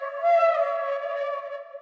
OZ-Vox (UHH).wav